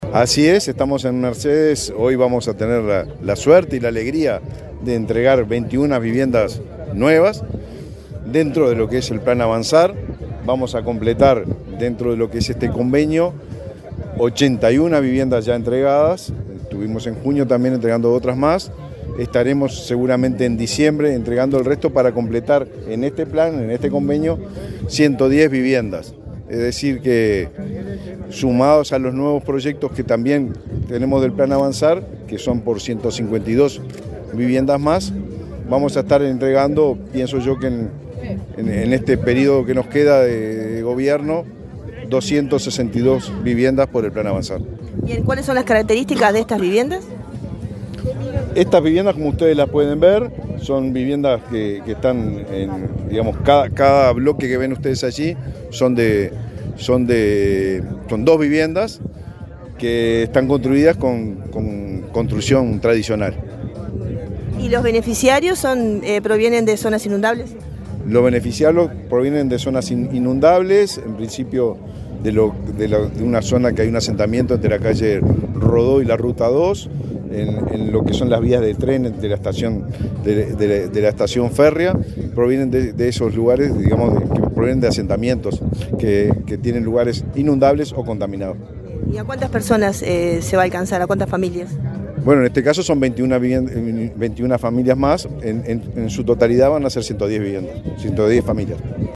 Declaraciones del ministro de Vivienda, Raúl Lozano
El ministro de Vivienda, Raúl Lozano, dialogó con la prensa en Soriano, antes de participar en la entrega de 21 casas para relocalización de familias